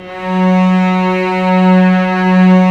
Index of /90_sSampleCDs/Roland LCDP13 String Sections/STR_Vcs II/STR_Vcs6 f Amb